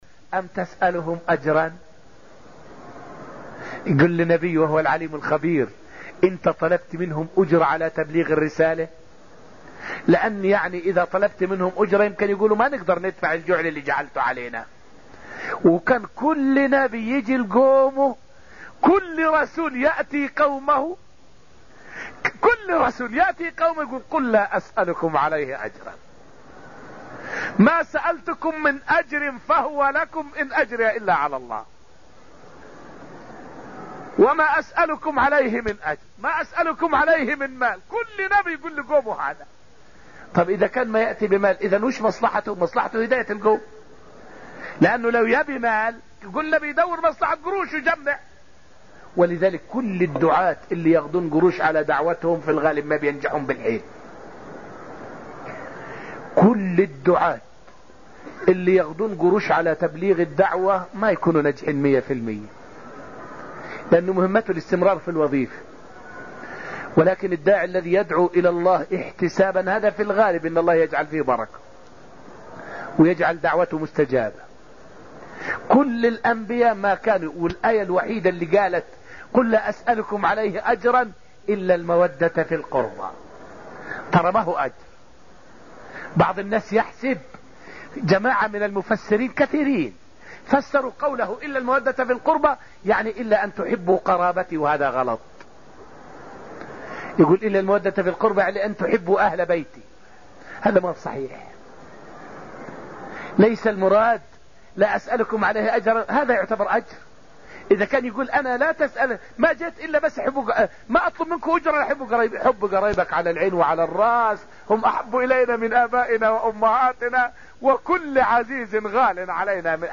فائدة من الدرس السادس من دروس تفسير سورة الطور والتي ألقيت في المسجد النبوي الشريف حول معنى {أم تسألهم أجرا فهم من مغرم مثقلون}.